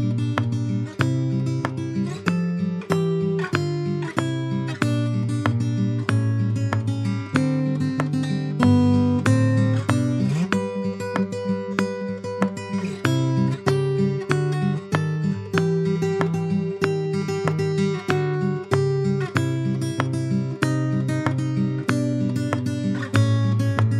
Pop (1960s)